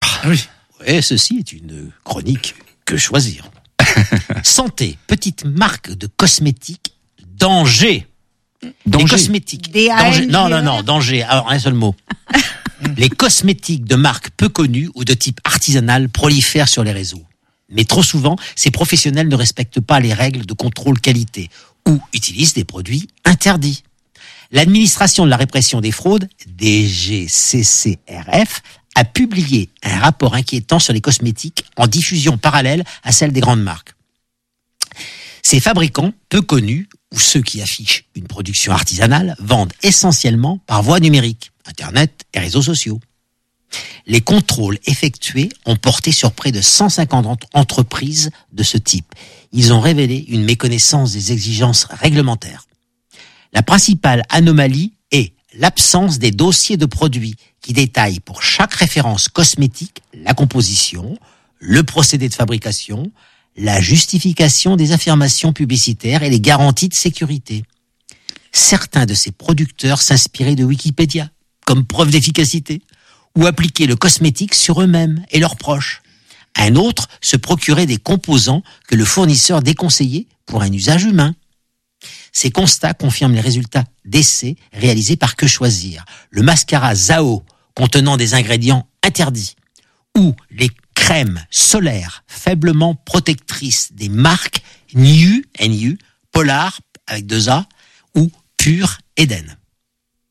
dans une ambiance très décontractée , certes dans un temps limité